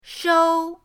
shou1.mp3